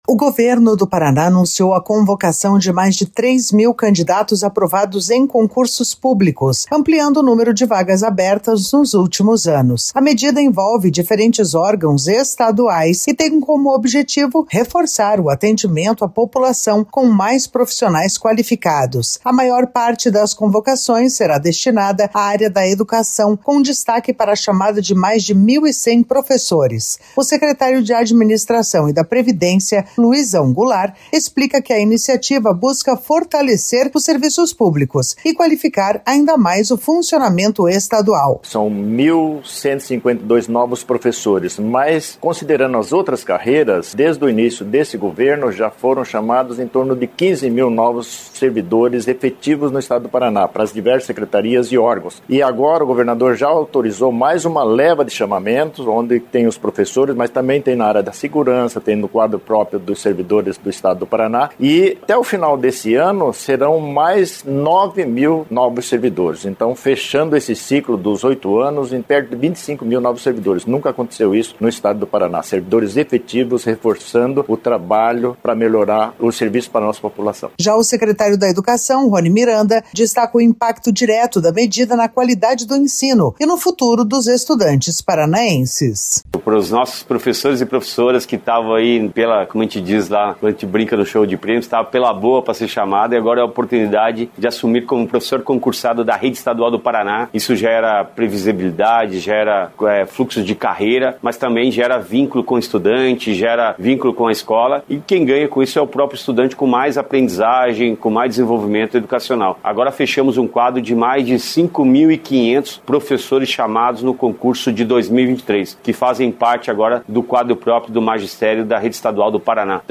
A maior parte das convocações será destinada à área da educação, com destaque para a chamada de mais de 1.100 professores.  O secretário da Administração e da Previdência, Luizão Goulart, explica que a iniciativa busca fortalecer os serviços públicos e qualificar ainda mais o funcionalismo estadual.
Já o secretário da Educação, Roni Miranda, destaca o impacto direto da medida na qualidade do ensino e no futuro dos estudantes paranaenses.